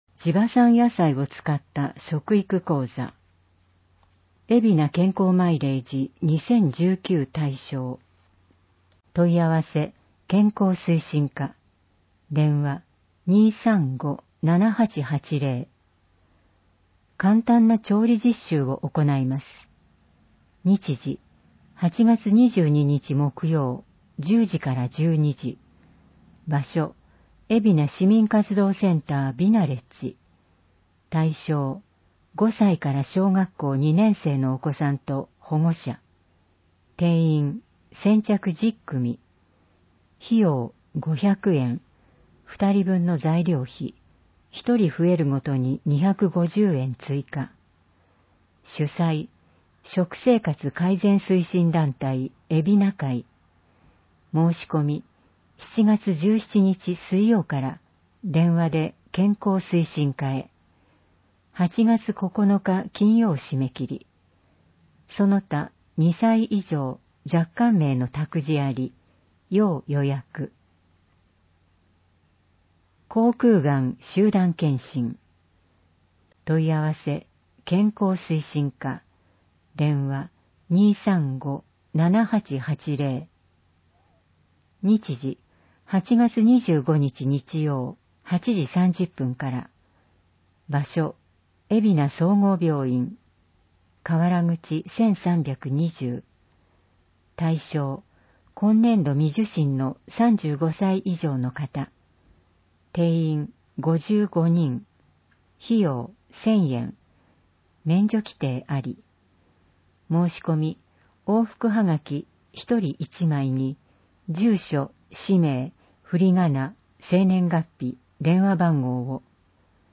広報えびな 令和元年7月15日号（電子ブック） （外部リンク） PDF・音声版 ※音声版は、音声訳ボランティア「矢ぐるまの会」の協力により、同会が視覚障がい者の方のために作成したものを登載しています。